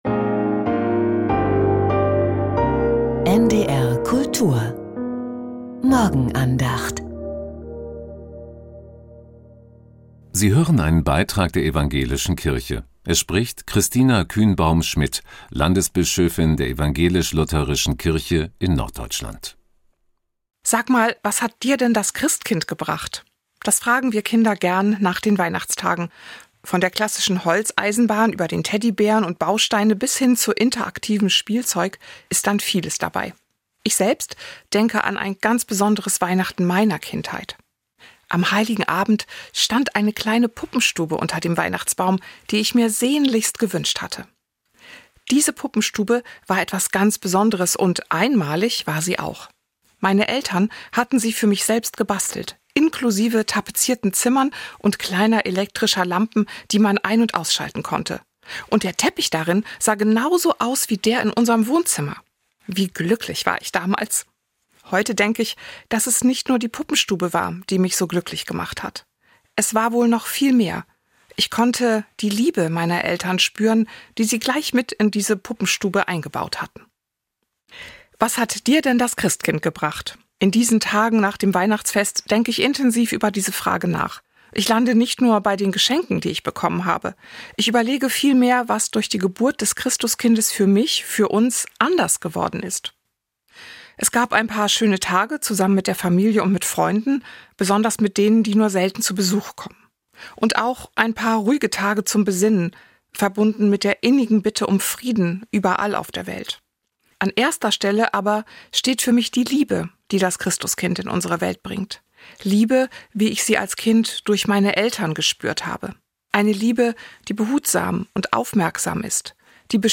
Was hat dir das Christkind gebracht? ~ Die Morgenandacht bei NDR Kultur Podcast